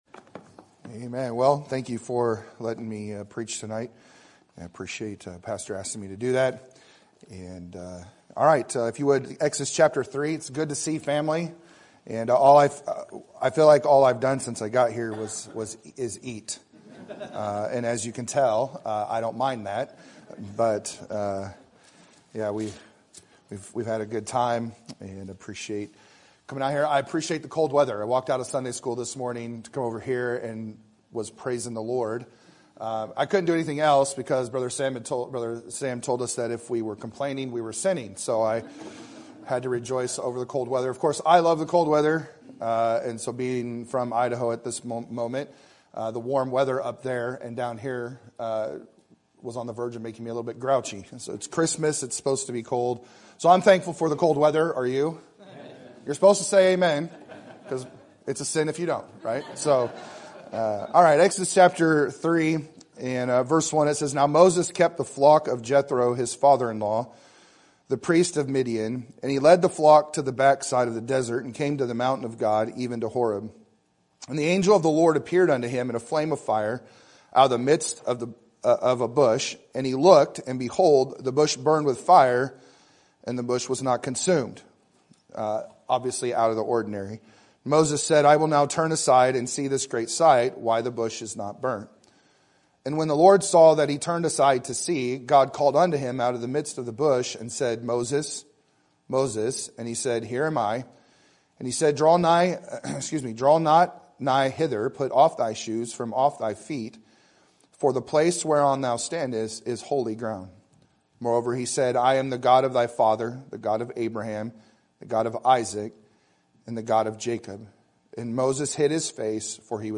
Sermon Date
Sermon Topic: General Sermon Type: Service Sermon Audio: Sermon download: Download (18.04 MB) Sermon Tags: Exodus Moses Disappointment God